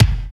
34 KICK.wav